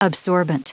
Prononciation du mot absorbant en anglais (fichier audio)
Prononciation du mot : absorbant